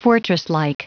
Prononciation du mot fortresslike en anglais (fichier audio)
Prononciation du mot : fortresslike